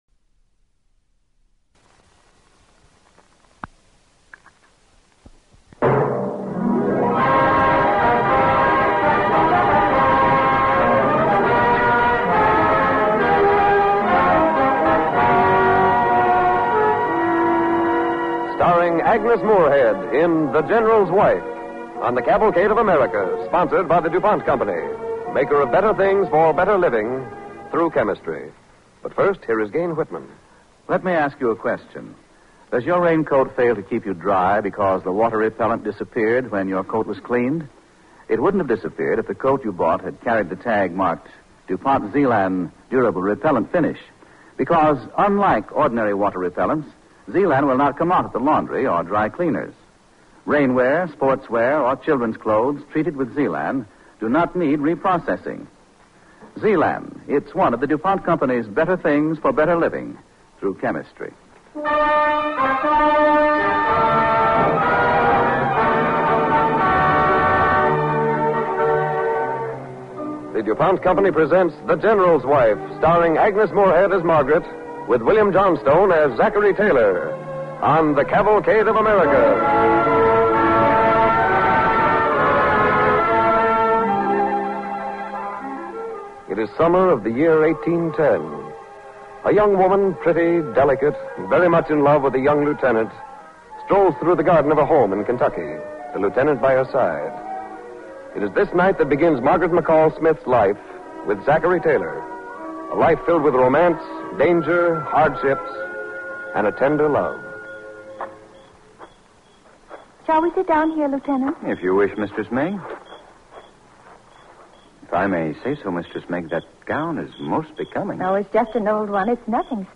The General's Wife, starring Agnes Moorehead and William Johnstone